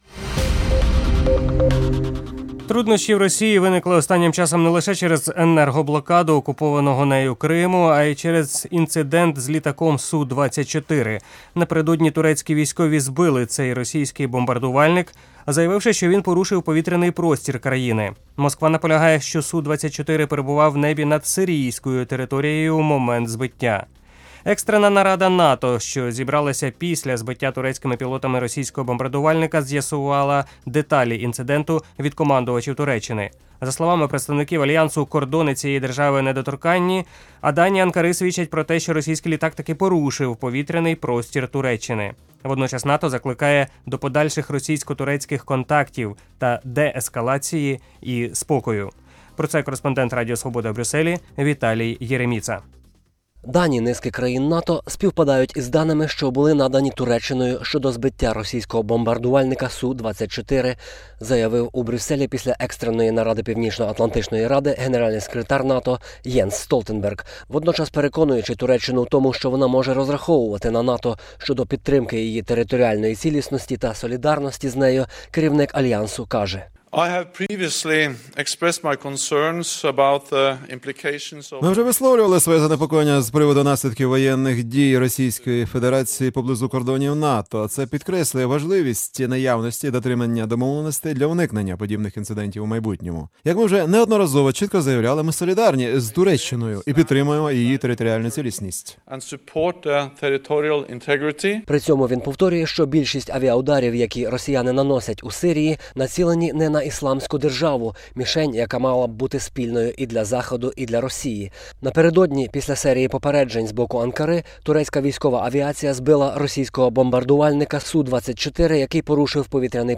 Очевидці зафіксували на відео падіння палаючого літака 24 листопада 2015 року